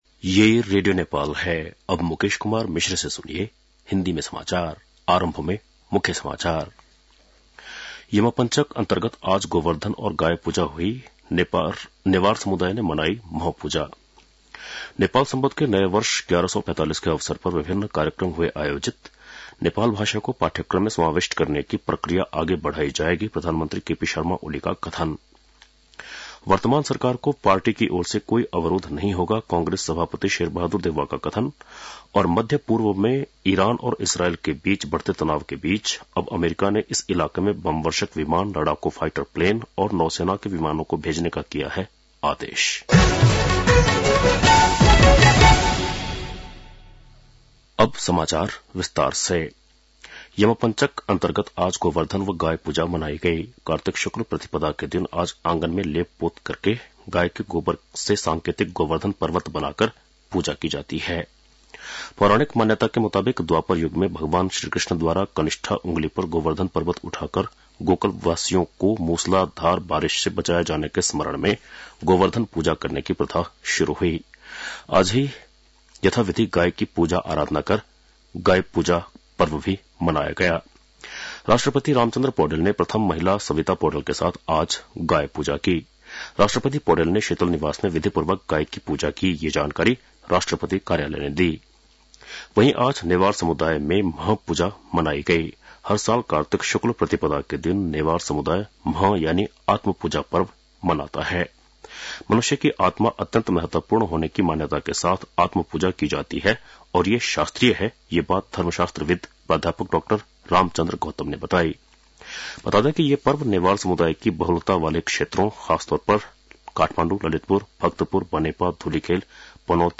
बेलुकी १० बजेको हिन्दी समाचार : १८ कार्तिक , २०८१